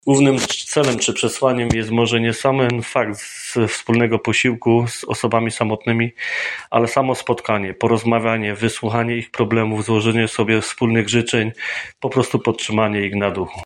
W podobnym tonie mówi zastępca wójta, Bogusław Wawak.